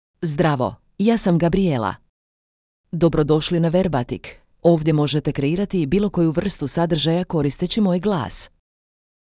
GabrijelaFemale Croatian AI voice
Gabrijela is a female AI voice for Croatian (Croatia).
Voice sample
Listen to Gabrijela's female Croatian voice.
Gabrijela delivers clear pronunciation with authentic Croatia Croatian intonation, making your content sound professionally produced.